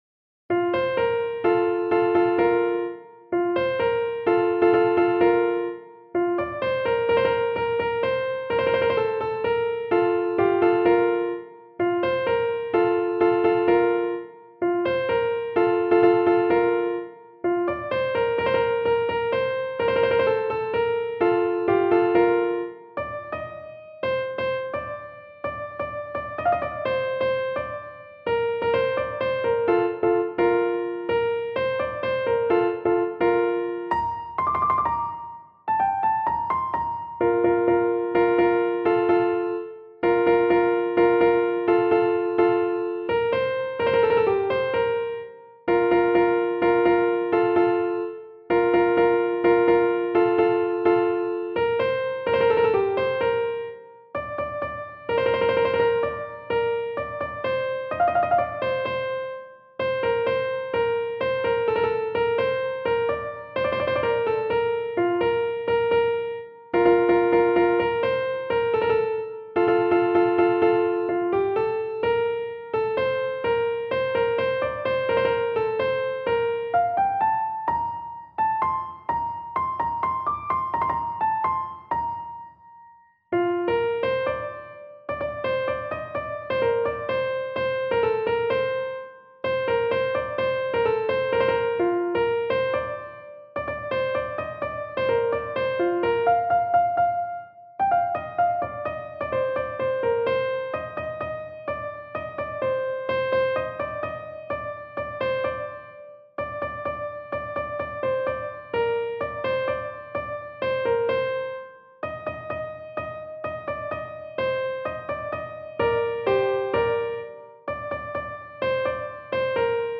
تنظیم شده برای کیبورد